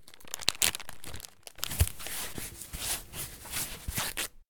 inv_bandage.ogg